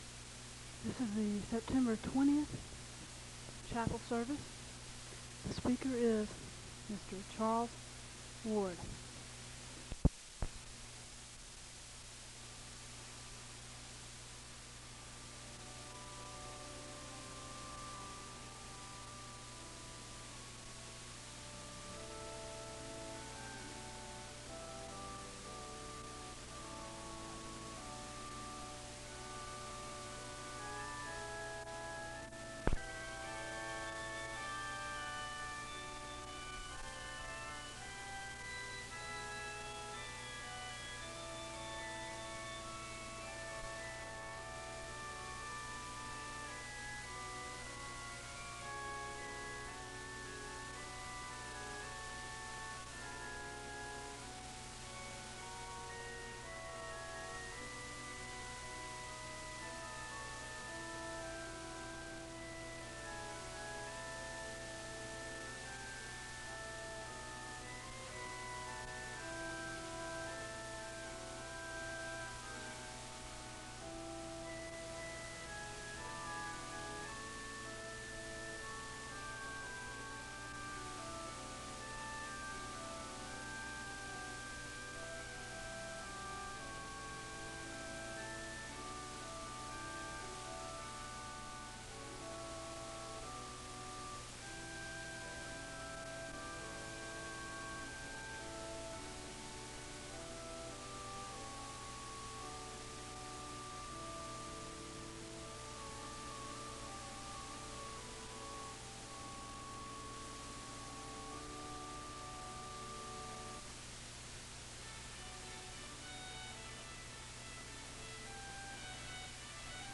SEBTS Chapel
The beginning part of the audio recording is poor quality.
The service begins with organ music (00:00-05:00). The speaker gives a word of prayer (05:00-07:19).
The choir sings the anthem (07:57-10:30).